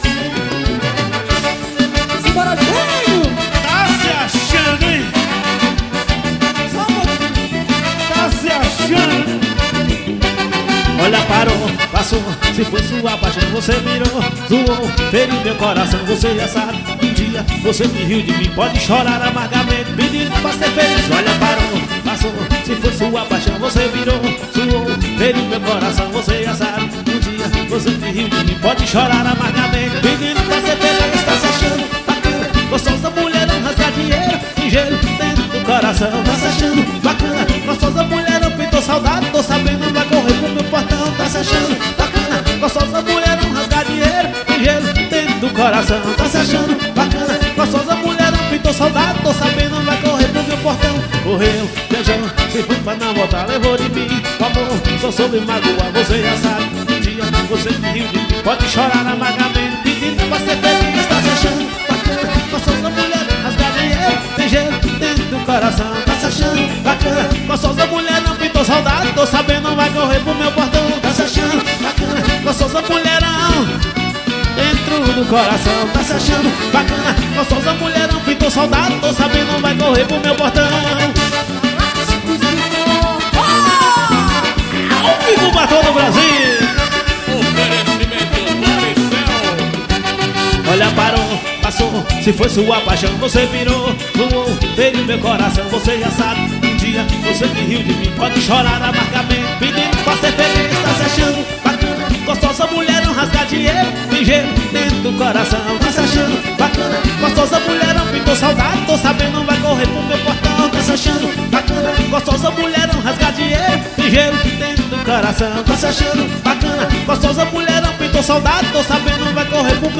Ao Vivo na Acrece.